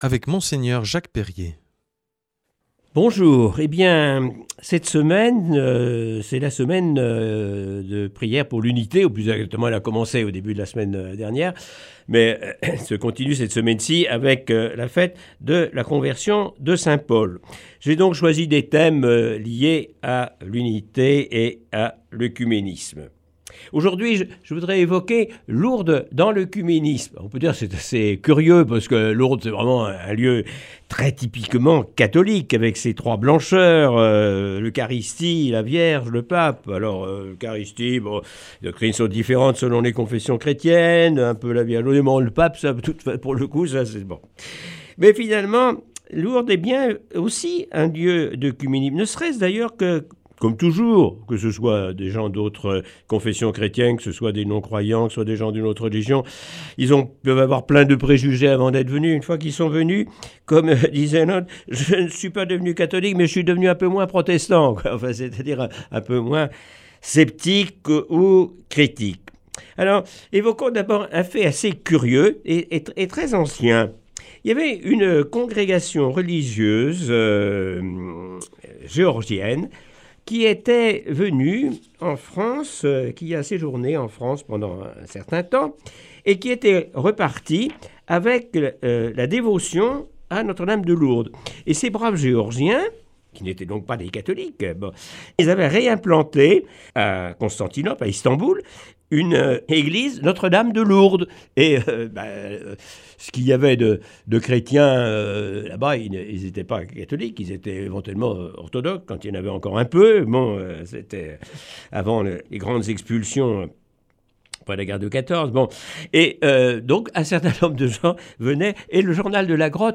lundi 22 janvier 2024 Enseignement Marial Durée 10 min
Aujourd’hui avec Mgr Jacques Perrier.